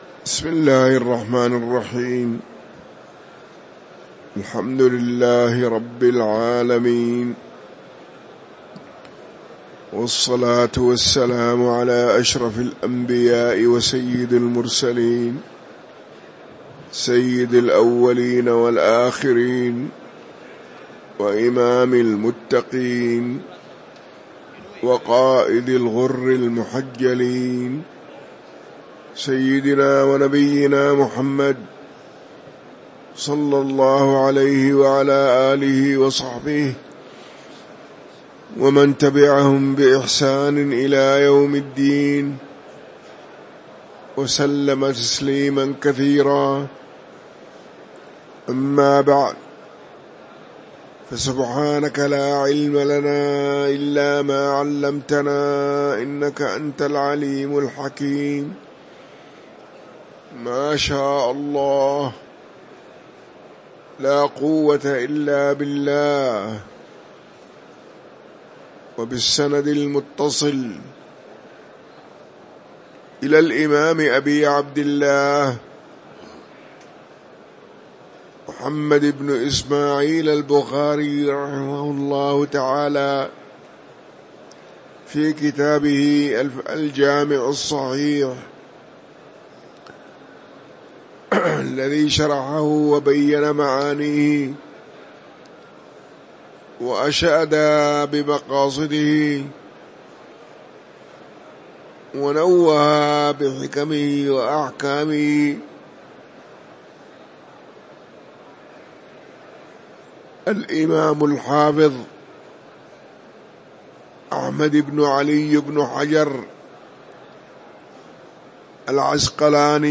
تاريخ النشر ١٩ محرم ١٤٤٤ هـ المكان: المسجد النبوي الشيخ